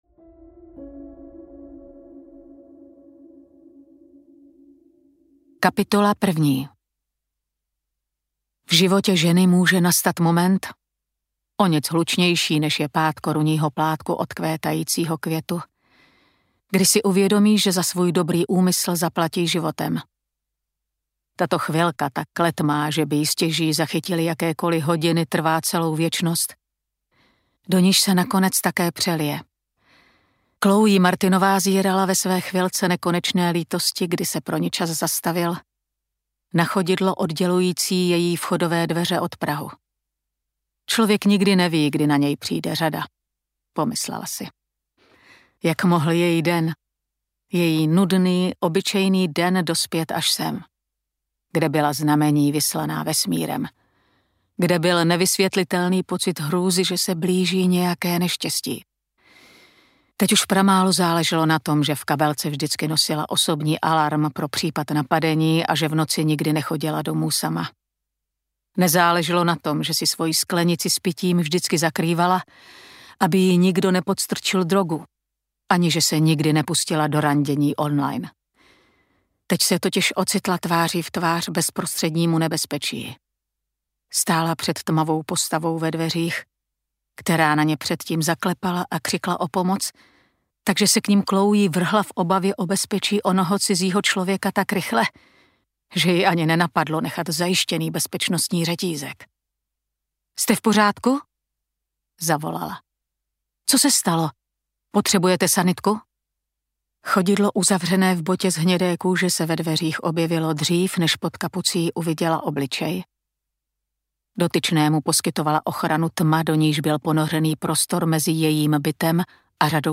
Profil zabijáka audiokniha
Ukázka z knihy